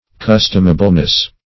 Search Result for " customableness" : The Collaborative International Dictionary of English v.0.48: Customableness \Cus"tom*a*ble*ness\, n. Quality of being customable; conformity to custom.